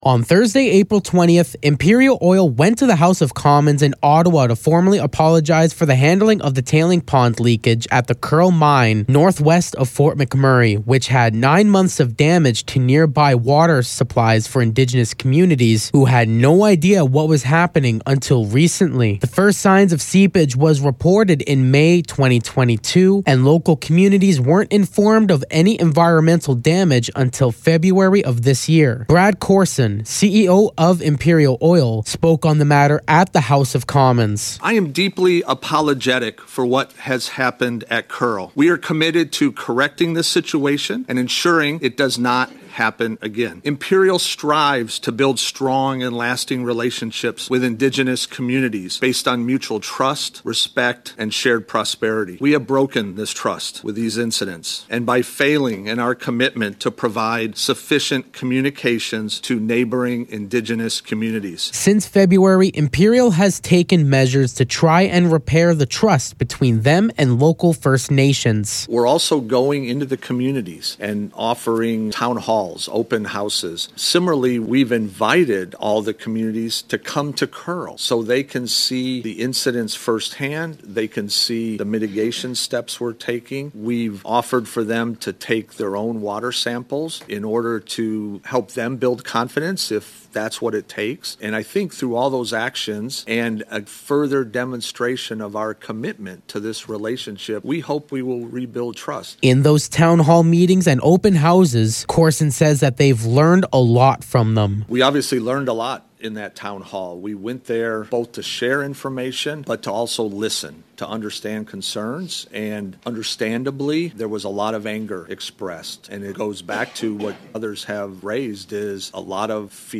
On Thursday, Imperial Oil stood appeared at the House of Commons in Ottawa to offer an apology for the handling of the tailing ponds leaks from the Kearl Mine northwest of Fort McMurray.
Imperial-Apology-Full-Audio.mp3